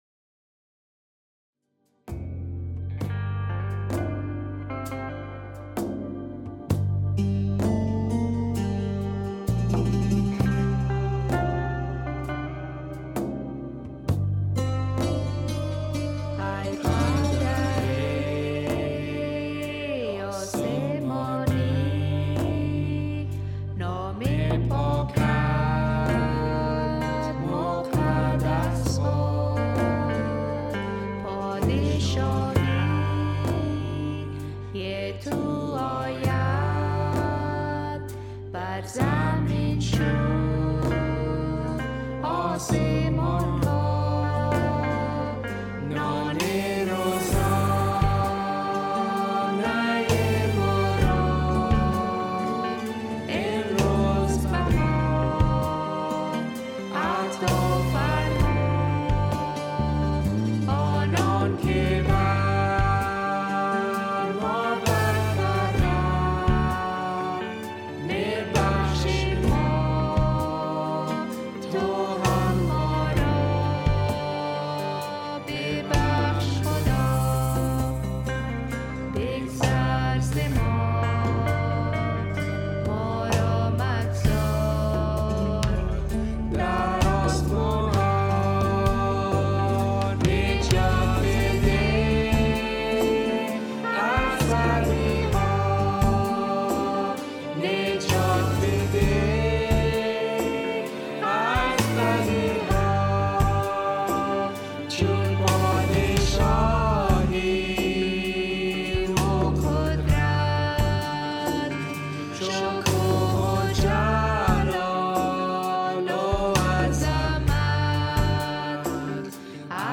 A worship song to bless our country, Afghanistan.